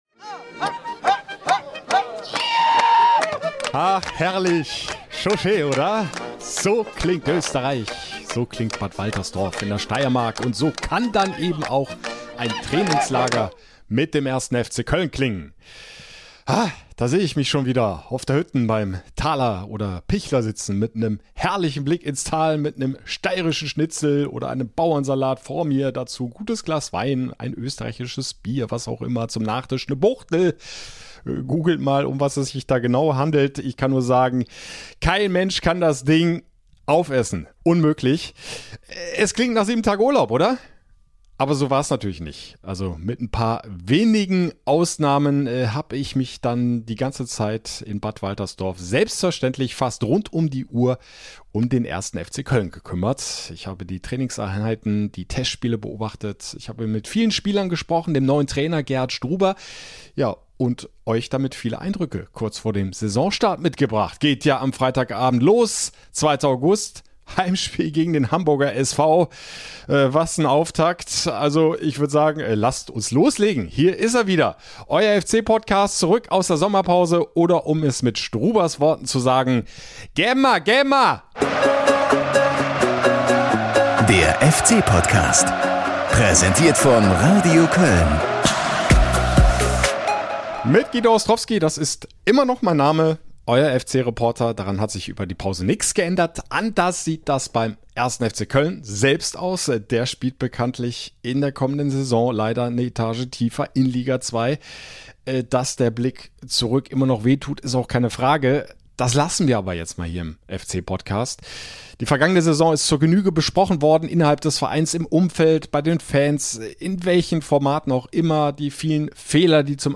Beschreibung vor 1 Jahr Die Sommerpause ist so gut wie vorbei und damit wird es höchste Zeit, dass auch euer FC-Podcast wieder zurück kehrt. Und diesmal aus Bad Waltersdorf in der Steiermark.